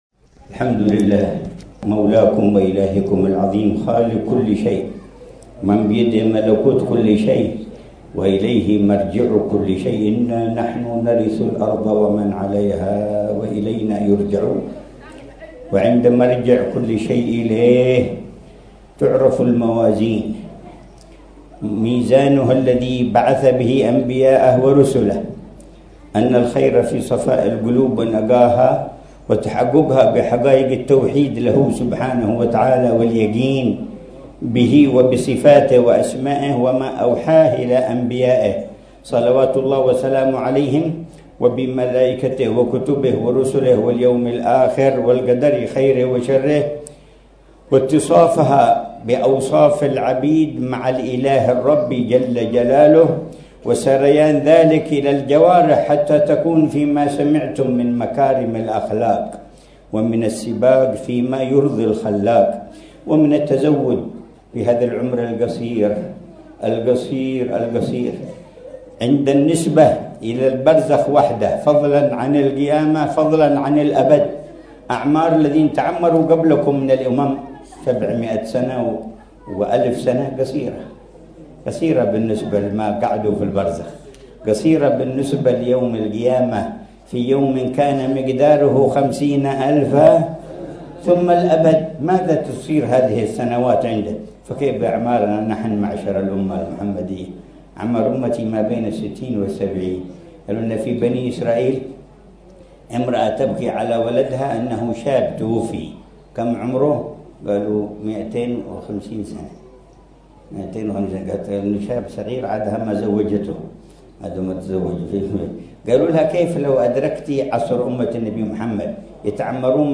مذاكرة العلامة الحبيب عمر بن محمد بن حفيظ في مجلس الذكر والتذكير في حولية الحبيب العلامة صالح بن عبد الله الحامد في وادي عمد، حضرموت، يوم الأربعاء 15 رجب 1446هـ بعنوان: